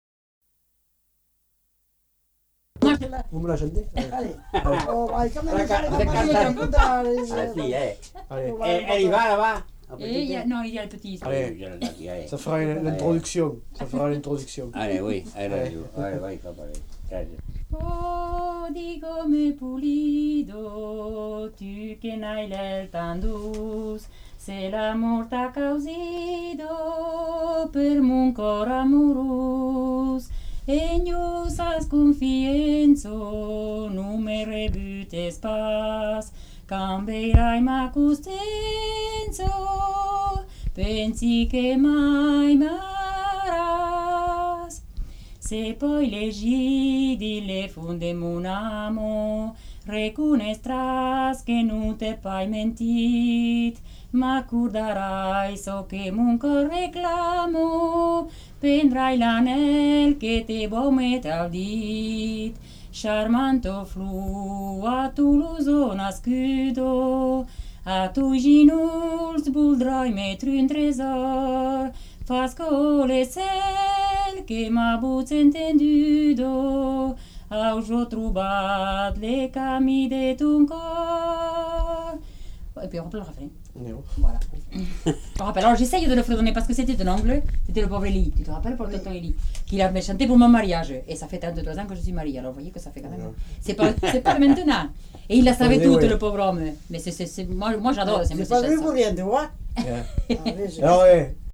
Aire culturelle : Lauragais
Lieu : Villaudric
Genre : chant
Effectif : 1
Type de voix : voix de femme
Production du son : chanté
Description de l'item : fragment ; 1 c. ; refr.